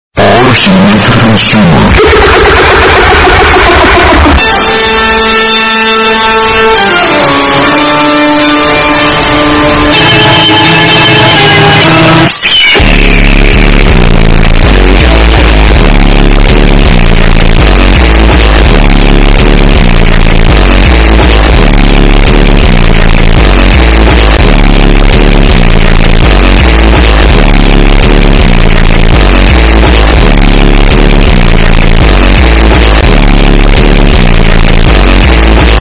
Really Loud Music Sound Button - Free Download & Play